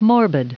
Prononciation du mot morbid en anglais (fichier audio)